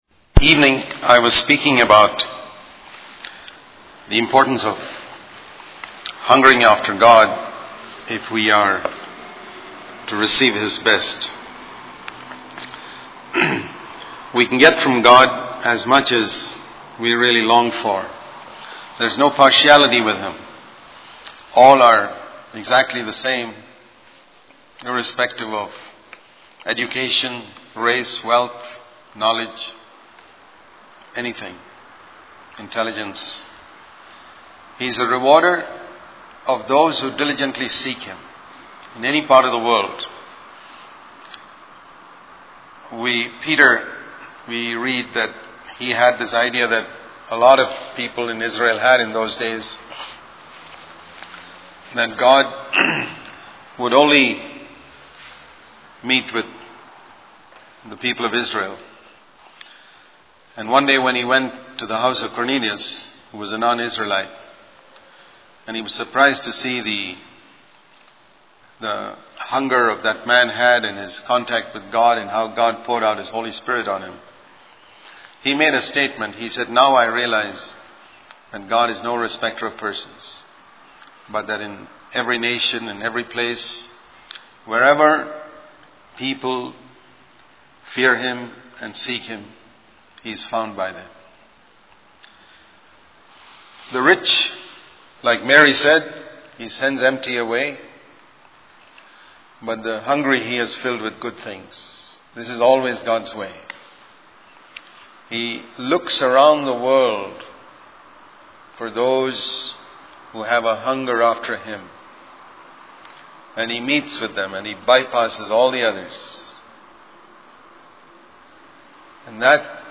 In this sermon, the preacher emphasizes that simply fasting, praying, and attending religious meetings does not guarantee salvation. He uses the story of the prodigal son to illustrate the difference between a worldly listener and a religious sinner.